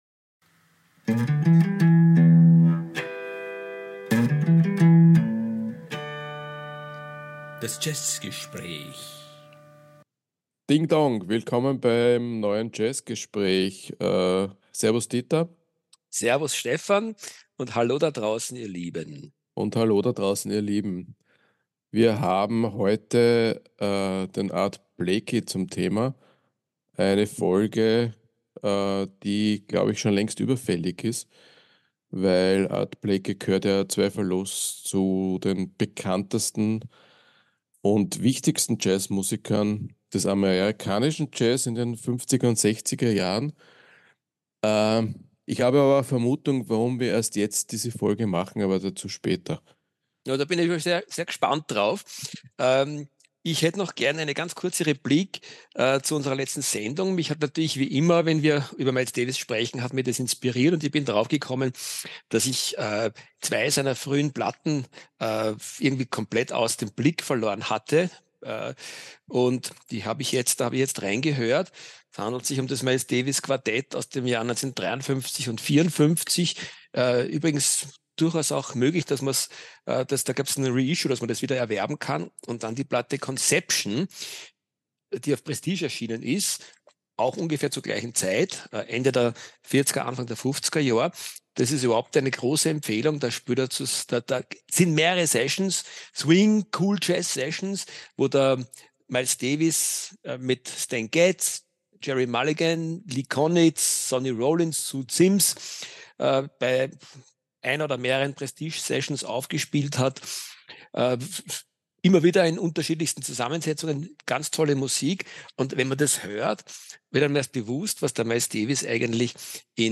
Wir fokussieren in dieser Folge unseres Jazzgesprächs deswegen auf seine Karriere in den späten 50er und frühen 60er Jahre.